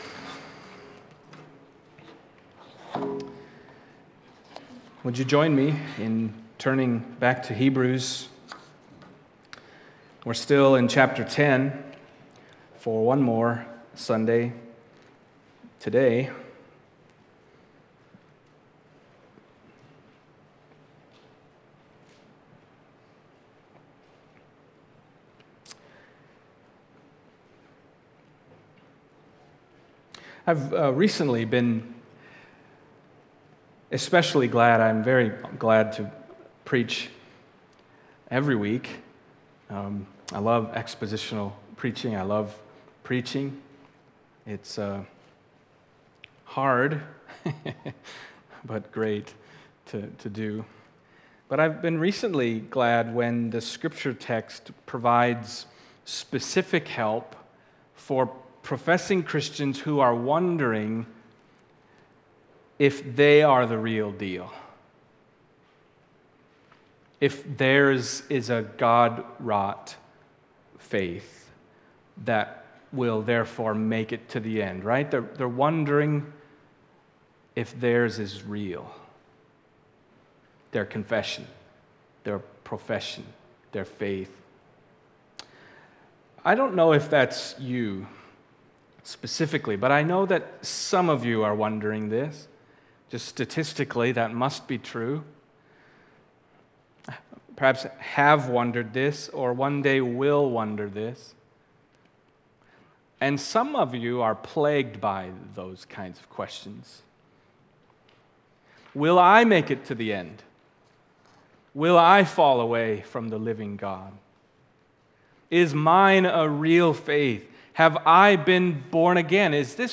Passage: Hebrews 10:32-39 Service Type: Sunday Morning